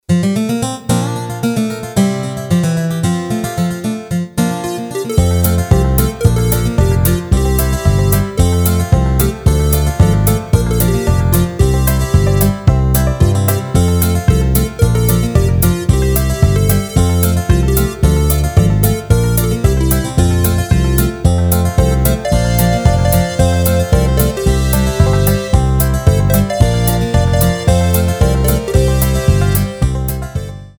Rubrika: Folk, Country
Karaoke
HUDEBNÍ PODKLADY V AUDIO A VIDEO SOUBORECH